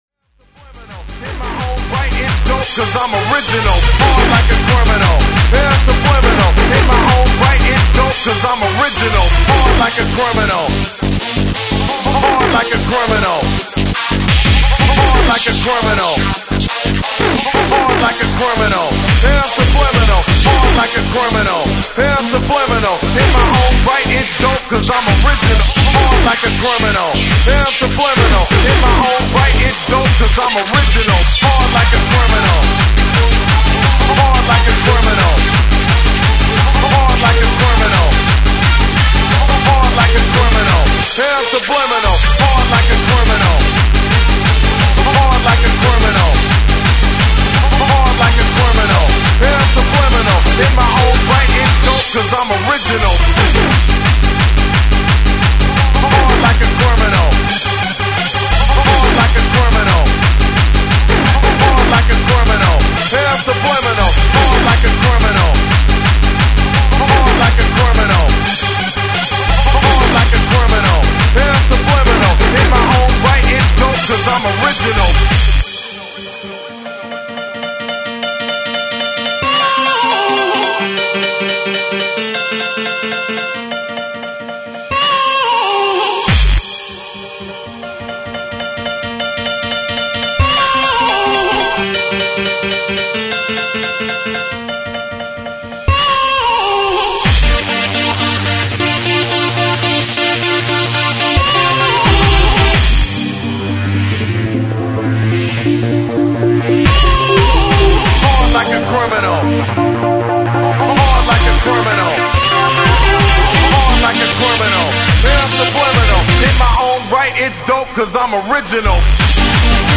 Freeform/Hardcore